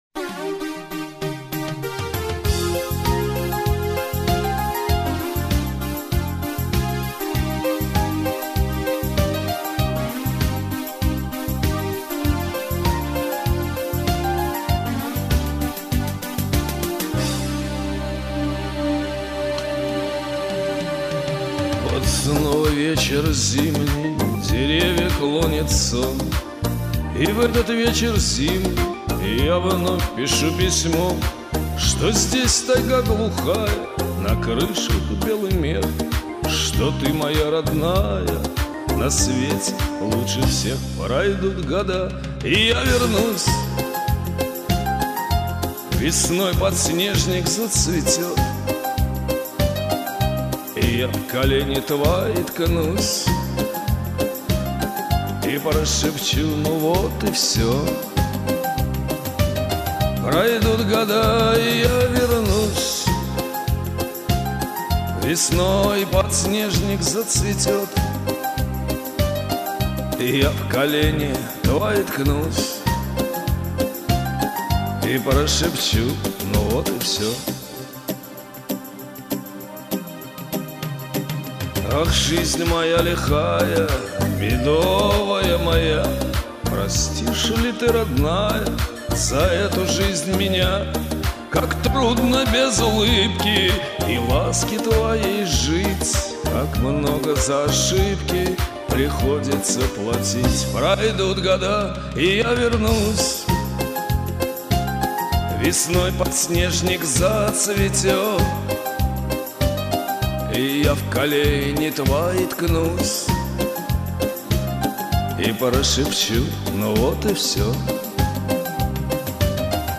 лёгкая грусть, чуть хриплый голос ... располагаешь